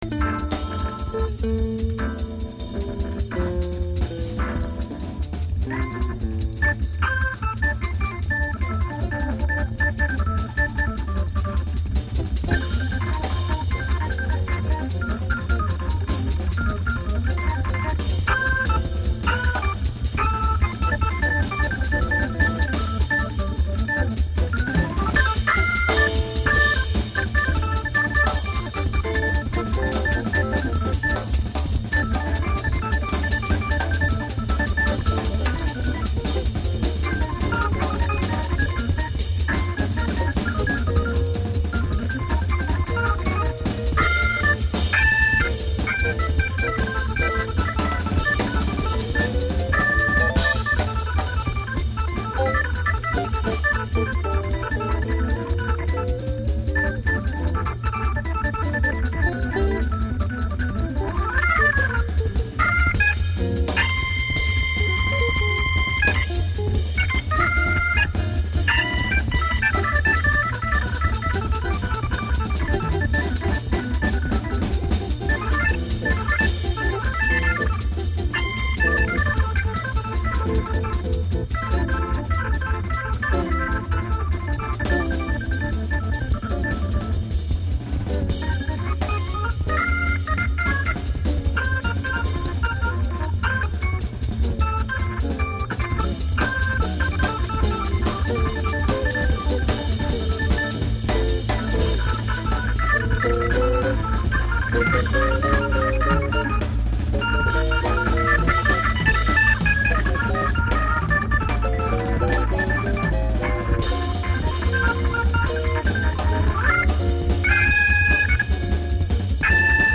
les basses sont dificilement definissables !
il faut reconaitre que l'ensemble envoie serieux 8)
guitare
sax
drums